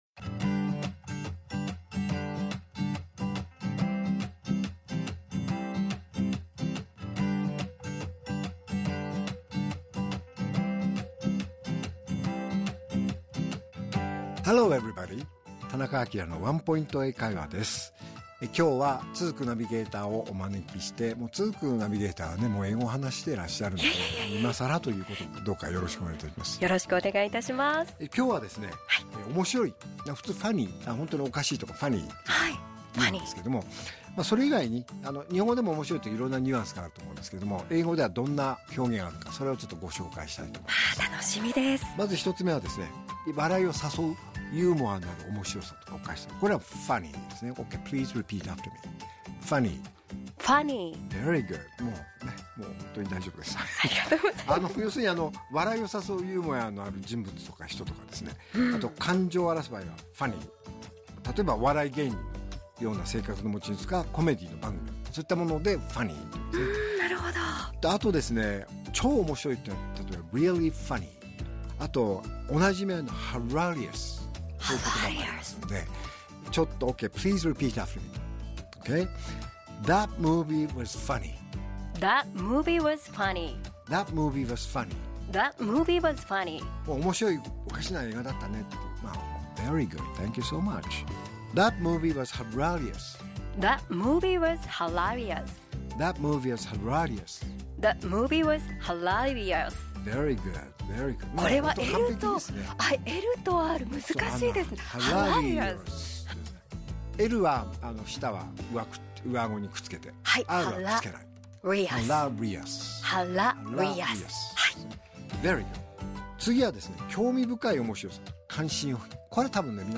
R6.5 AKILA市長のワンポイント英会話